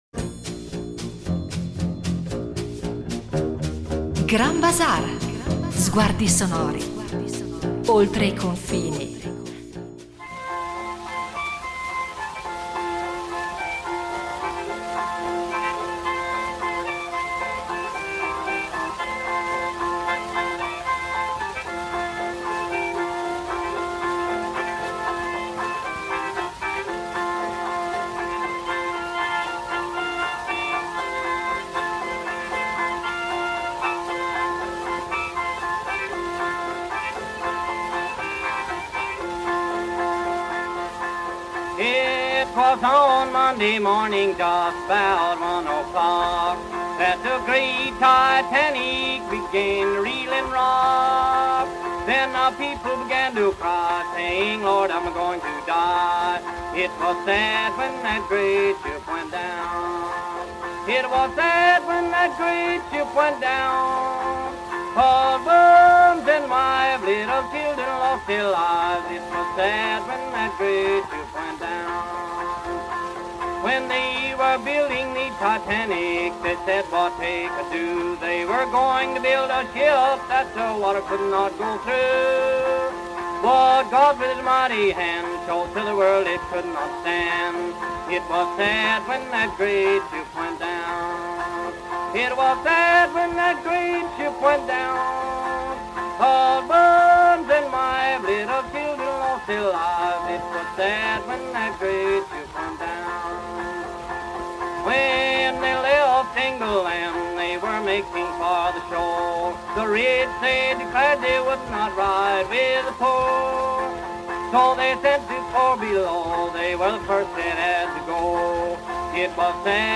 Un mosaico musicale che, più di cento anni dopo, continua a parlarci di fragilità umana, destino e speranza. 45:11 Le canzoni del Titanic Grand Bazaar 02.10.2025